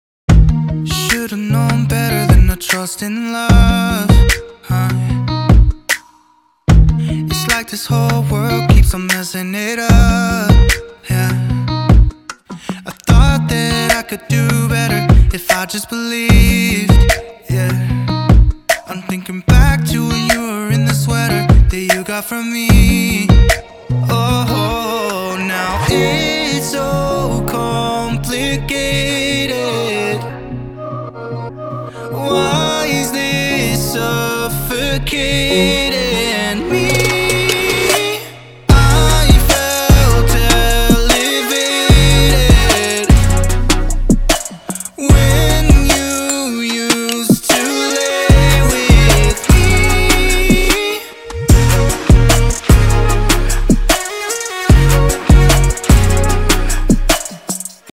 包含5条完整的无伴奏曲音轨，由两位专业歌手录制和编辑，一男一女。
除了这些高质量的无伴奏合唱之外，我们还包括75个旋律ad-lib和20个很棒的人声回路。